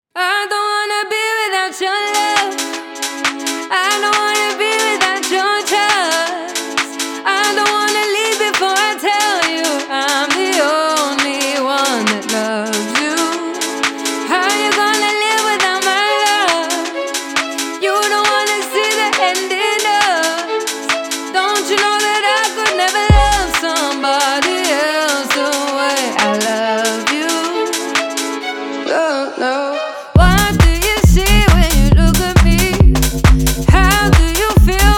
House Dance
Жанр: Танцевальные / Хаус